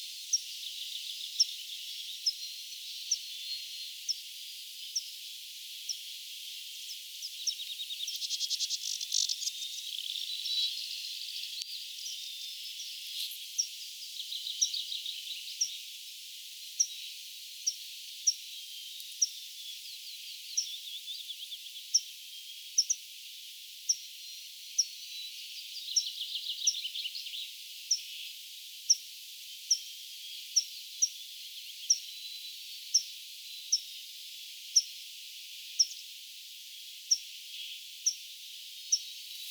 niittykirvislinnun huomioääntelyä
Tämä ei mielestäni ole mikään varoitusääni,
niittykirvislinnun_huomioaantelya.mp3